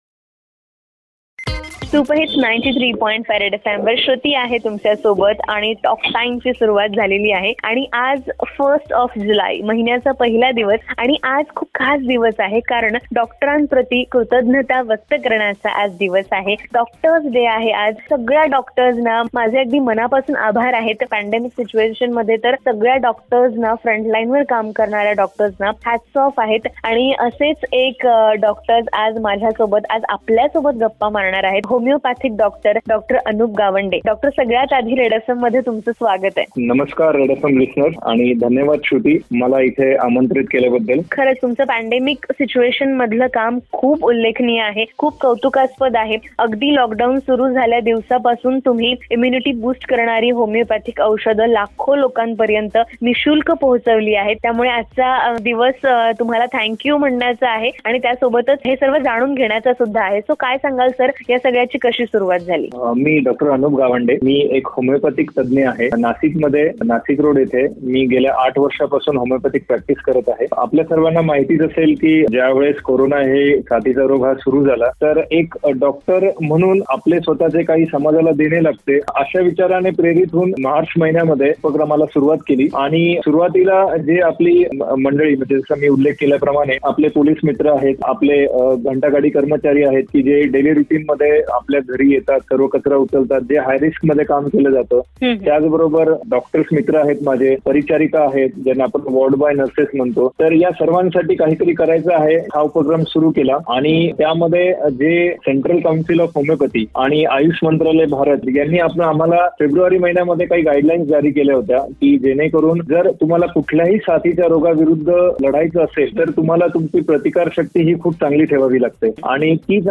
DOCTORS DAY SPECIAL INTERVIEW PART 1